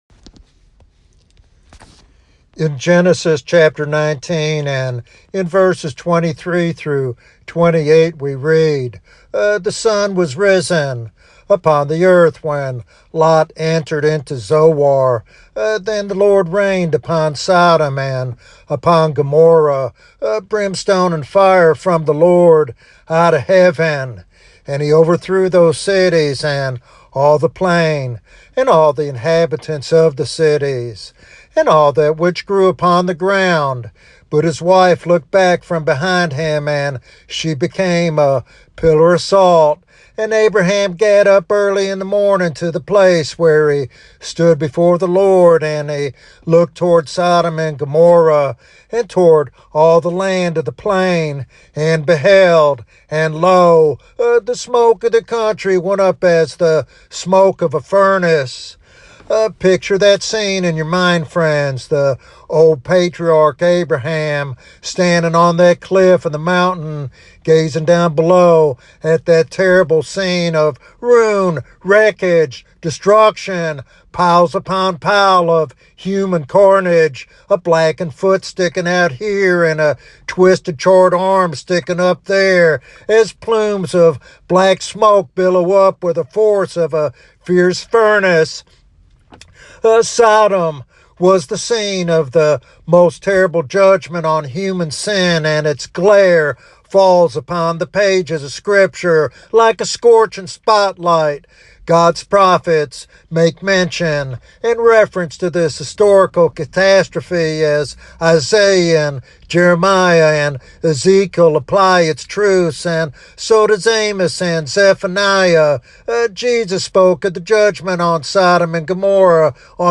This evangelistic sermon challenges believers and unbelievers alike to consider the reality of hell and the necessity of holiness.